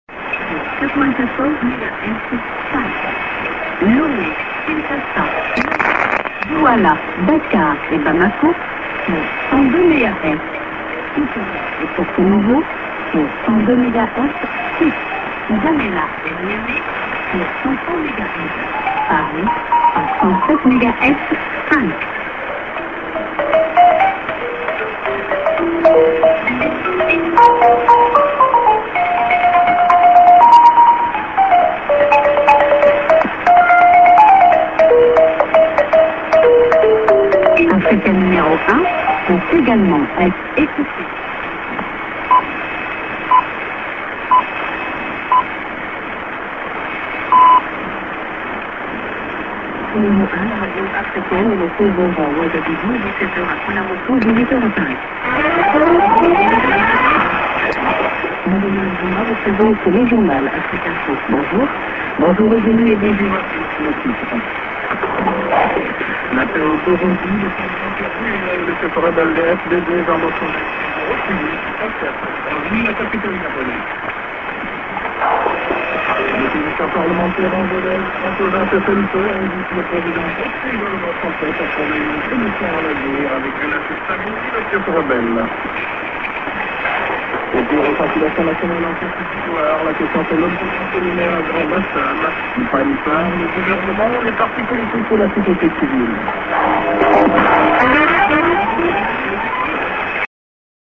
St. ->IS->ID(women)->00'45":TS->ID(women)->SJ->